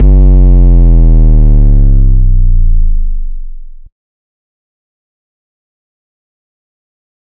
DMV3_808 2.wav